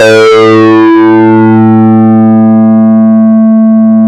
JP8 POLY R00.wav